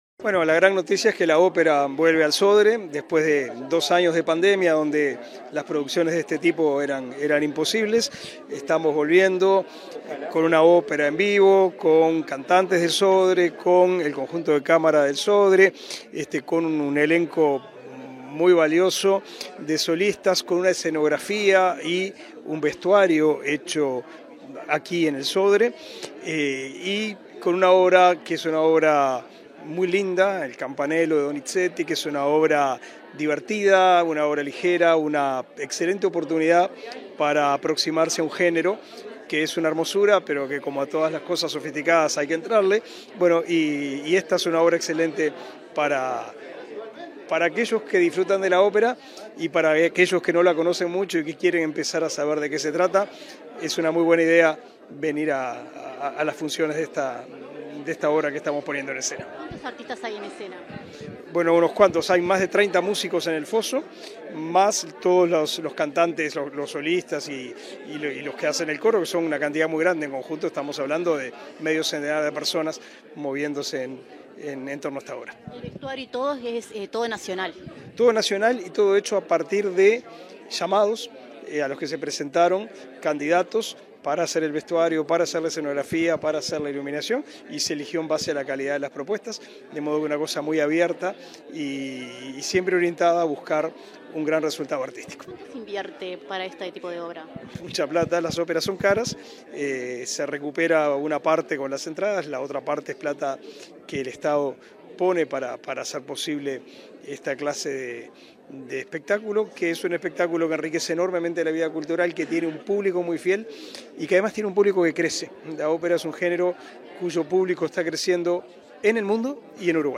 Entrevista al ministro de Educación y Cultura, Pablo da Silveira
Entrevista al ministro de Educación y Cultura, Pablo da Silveira 03/08/2022 Compartir Facebook X Copiar enlace WhatsApp LinkedIn Tras participar en el lanzamiento de la ópera “Il Campanello” del Coro Nacional del Sodre y el Conjunto Nacional de Música de Cámara , que se estrenará el 17 de setiembre, en la sala Eduardo Fabini del Auditorio Nacional Adela Reta, el ministro Pablo da Silveira efectuó declaraciones a Comunicación Presidencial.